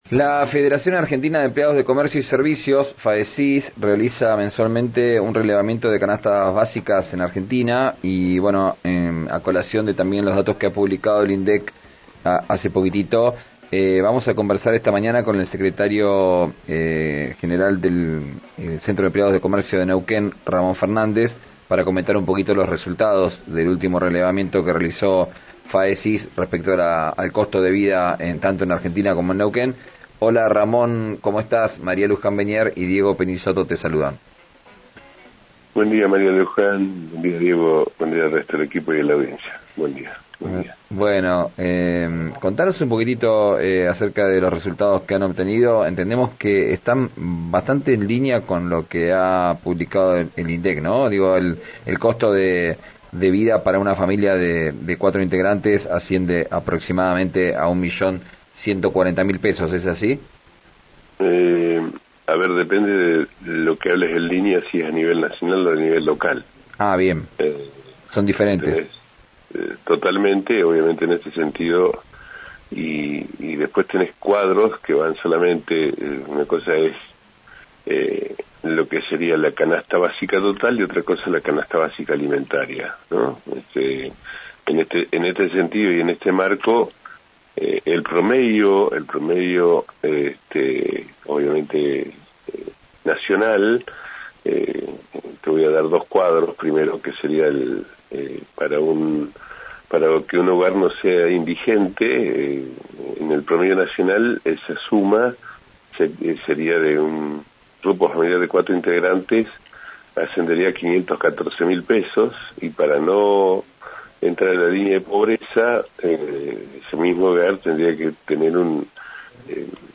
Escuchá a Ramón Fernández, secretario general del Centro de Empleados de comercio de Neuquén, en RÍO NEGRO RADIO:
Ramón Fernández, secretario general del Centro de Empleados de Comercio del Neuquén y diputado provincial, habló este martes en RÍO NEGRO RADIO sobre el relevamiento nacional que realiza la FAECyS sobre las canastas básicas en todo el país. Aseguró que los datos evidencian un grave desfasaje entre los salarios y el costo de vida real que enfrentan los trabajadores.